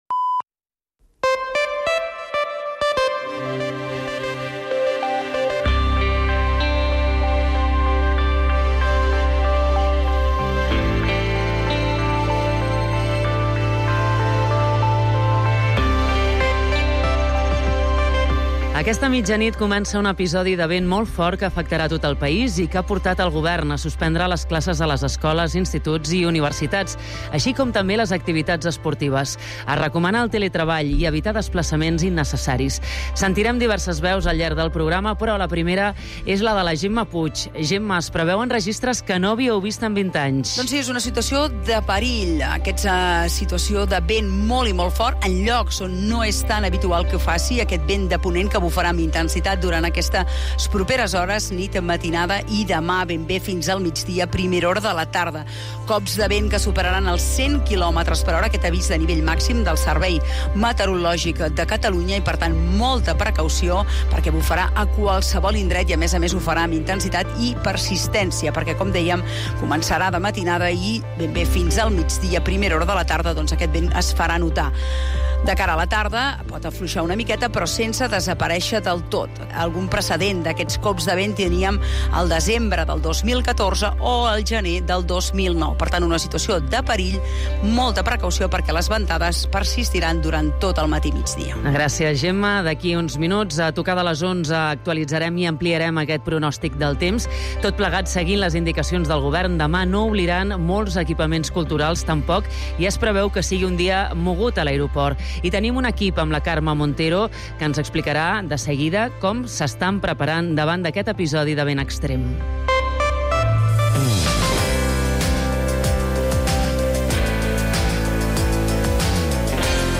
l’informatiu nocturn de Catalunya Ràdio